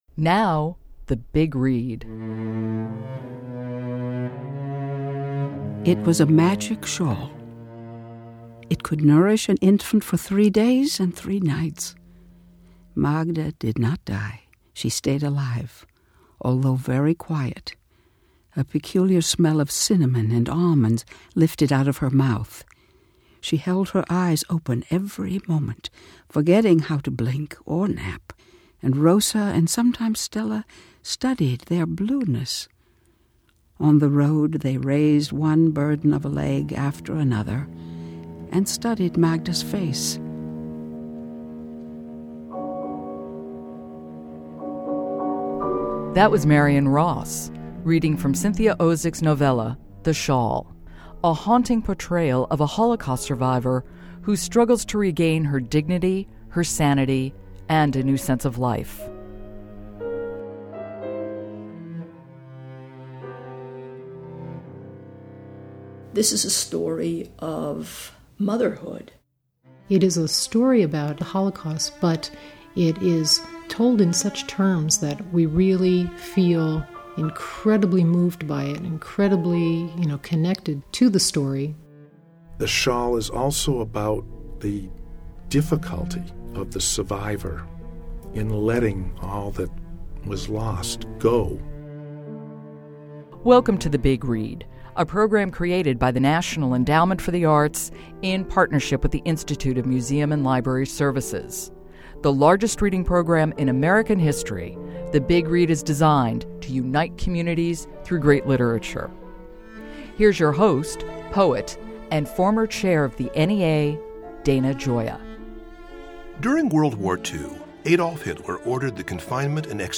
Marion Ross reads from The Shawl...